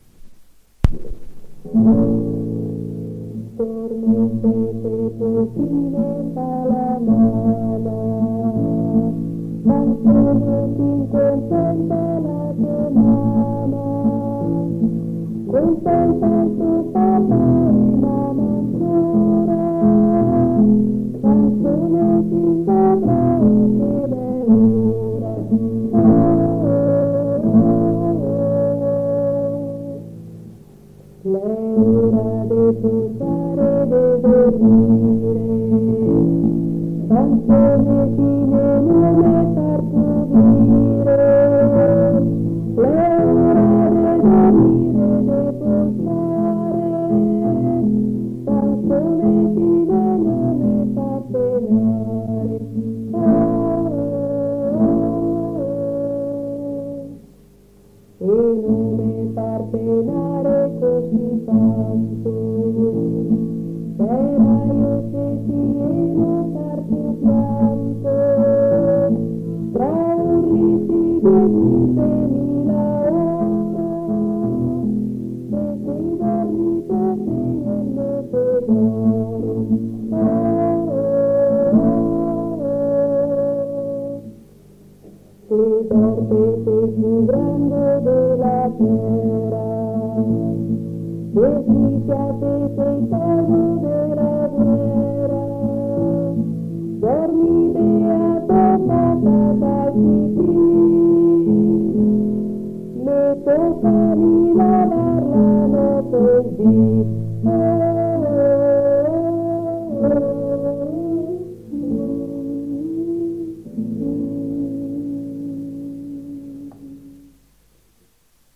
7 giugno 1978». 1975. 1 bobina di nastro magnetico.